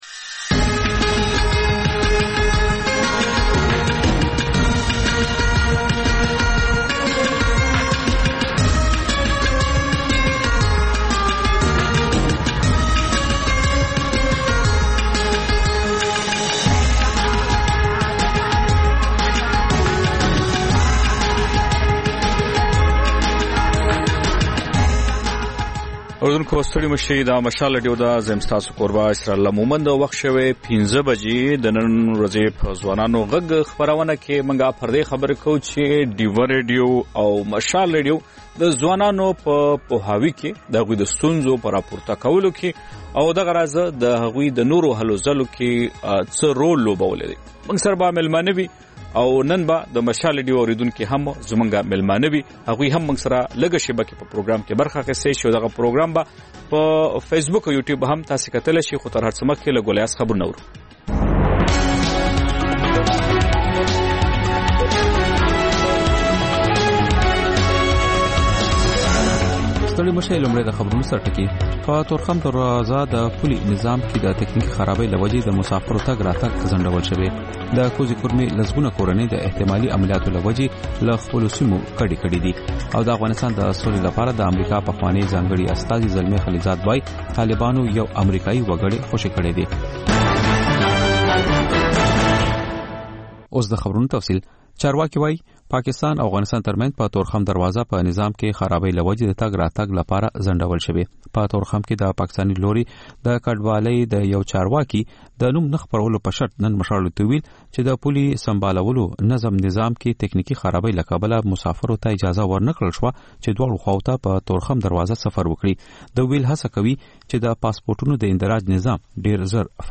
د مشال راډیو ماښامنۍ خپرونه. د خپرونې پیل له خبرونو کېږي، بیا ورپسې رپورټونه خپرېږي. ورسره یوه اوونیزه خپرونه درخپروو.